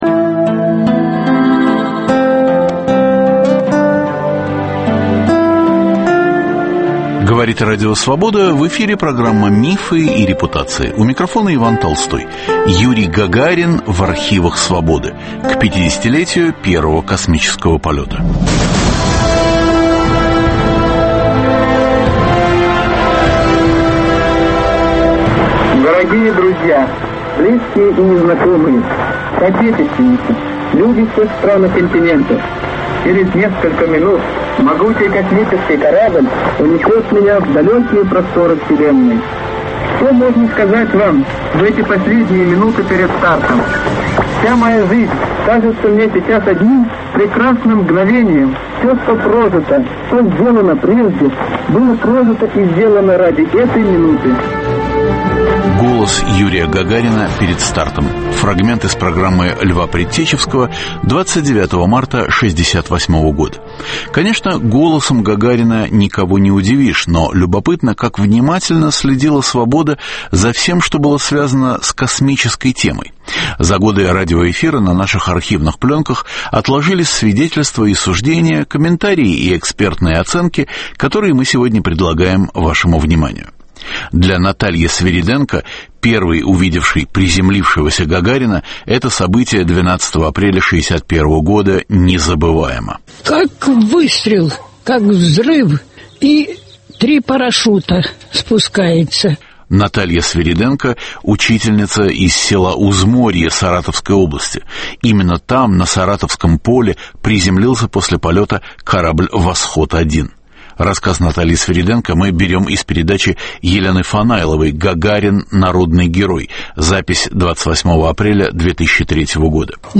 За годы вещания на наших архивных пленках отложились свидетельства и суждения, комментарии и экспертные оценки, которые мы сегодня предлагаем вашему вниманию.